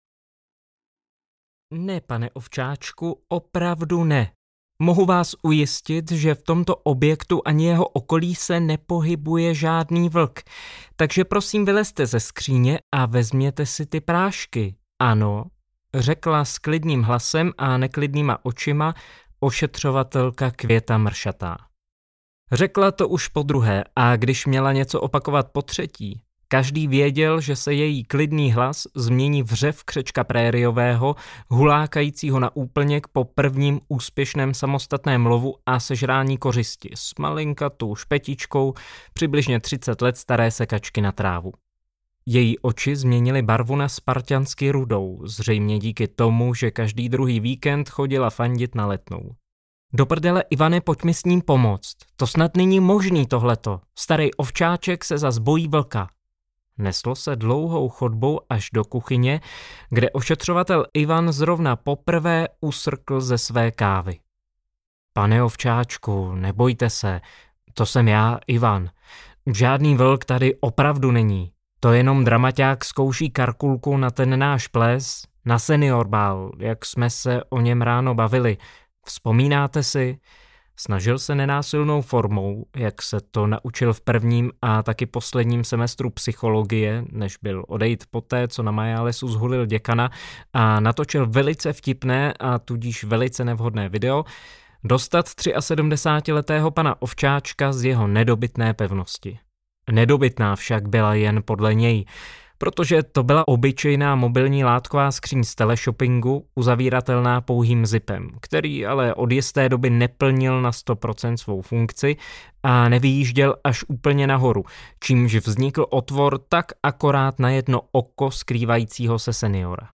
Jednorožec audiokniha
Ukázka z knihy
jednorozec-audiokniha